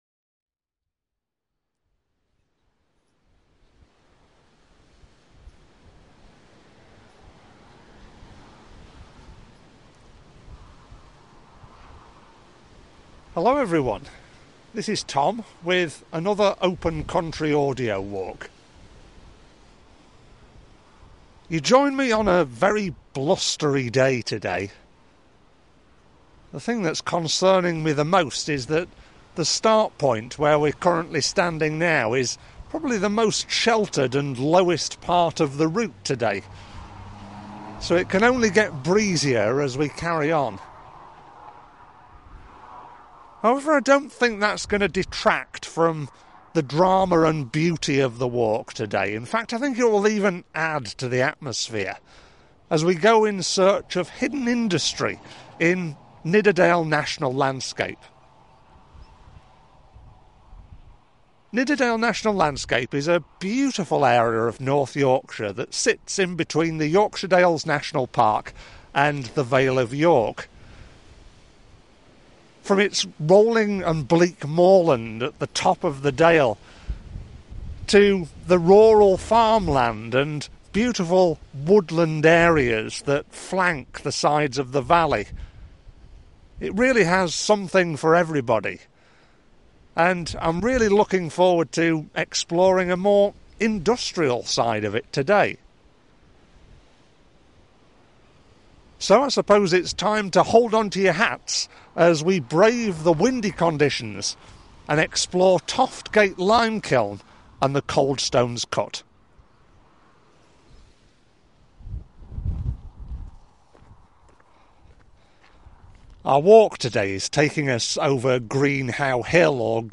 Toft Gate and Coldstones Cut Audio Walk
Toft-Gate-Coldstones-Cut-Audio-Walk.mp3